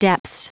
Prononciation du mot depths en anglais (fichier audio)
Prononciation du mot : depths